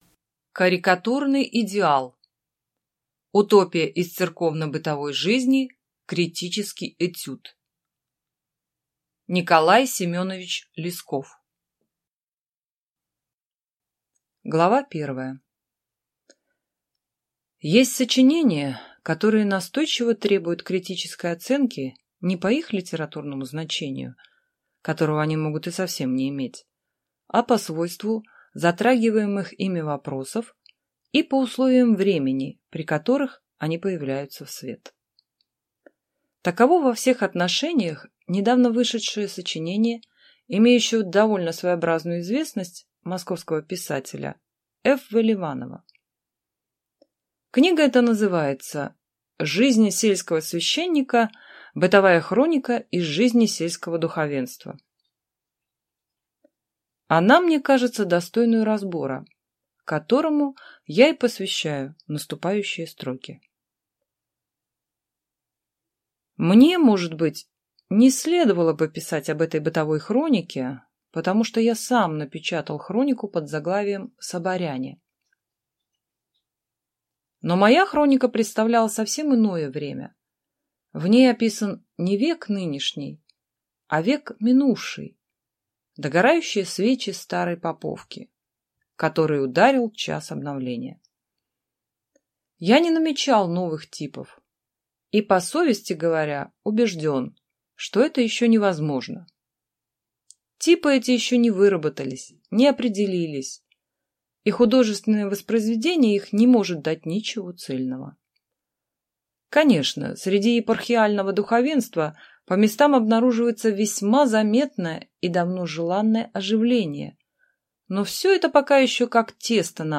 Аудиокнига Карикатурный идеал | Библиотека аудиокниг